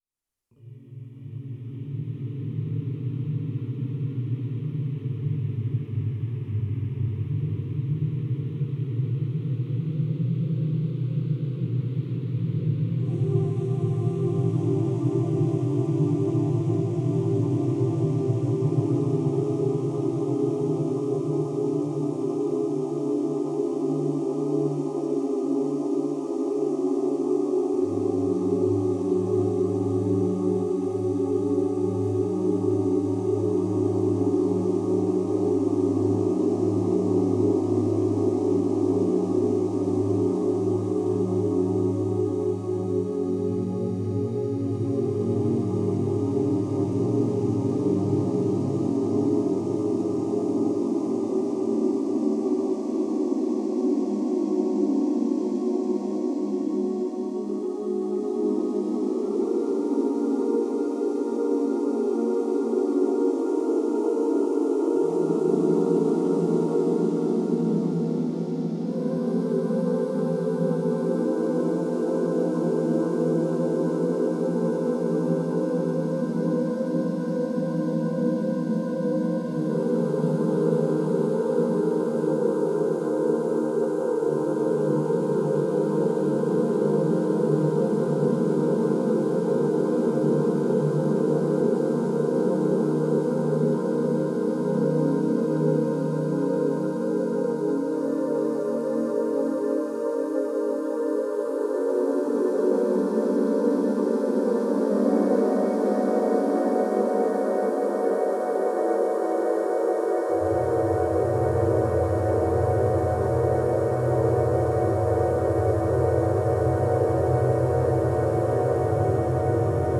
Soundtrack psychedelisiche Atmosphäre.
Synthie Vocals in Dissonanzen. AN- und Abschwellend.